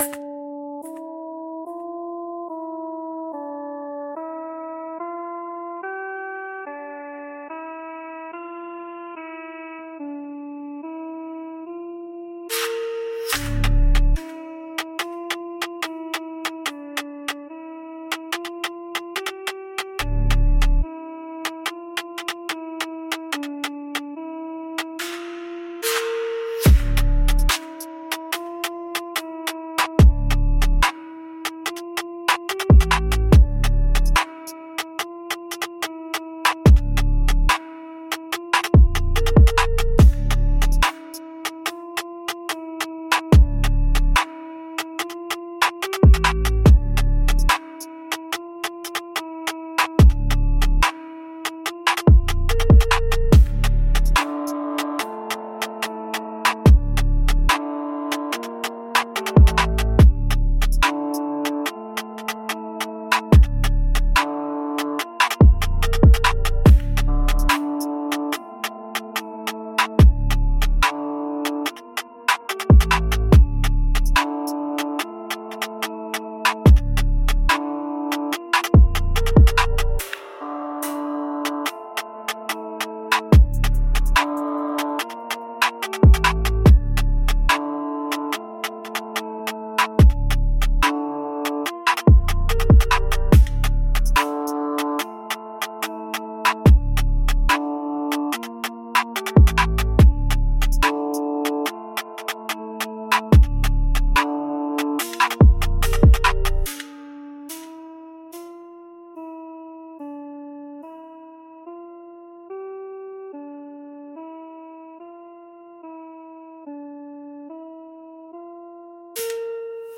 D Minor -72 BPM
Drill
Trap